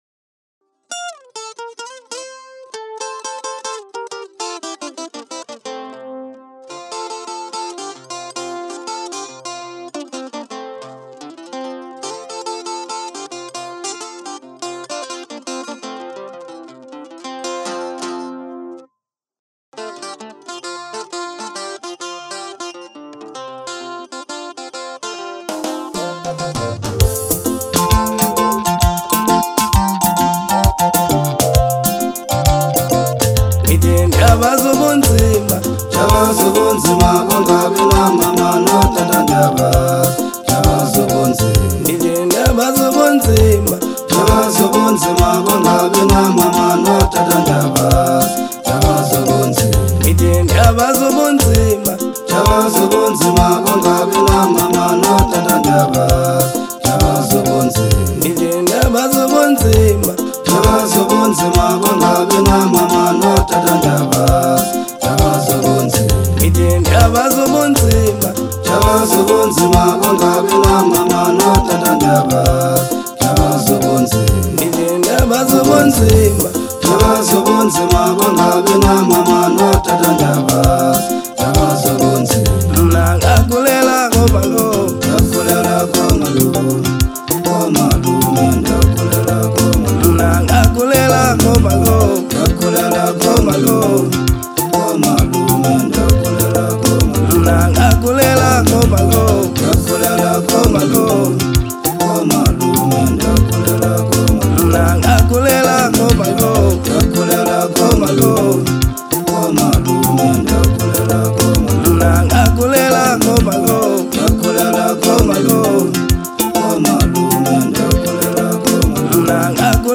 Genre : Maskandi